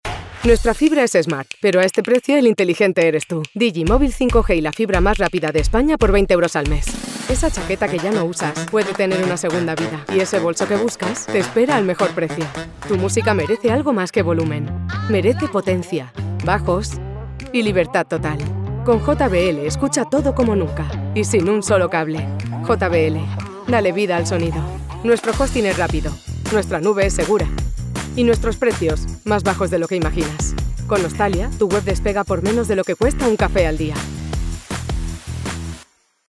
Promos
European Spanish female voice over talent at your disposal!
I have a current, relatable, on trend voice, that is bright, clear, informative and engaging.
Iberian Spanish accent. 🙂